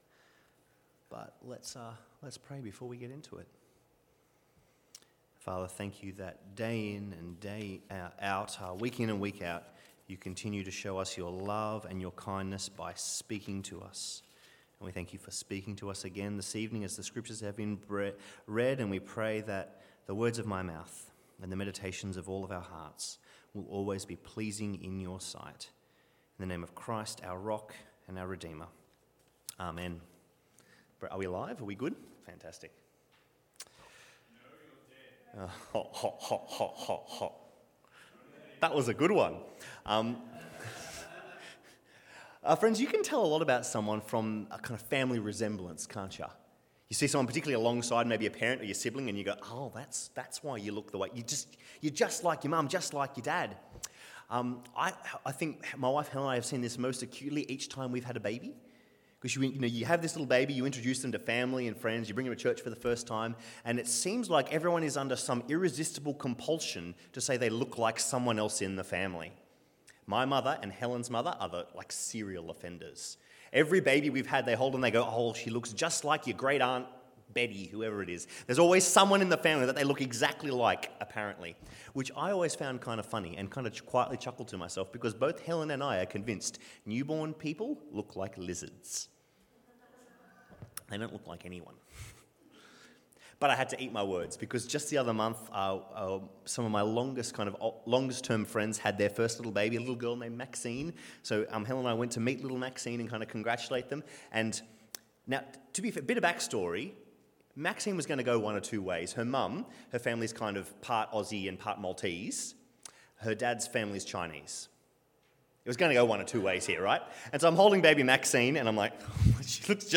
Service Type: Rosemeadow AM